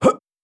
Player_Jump 01.wav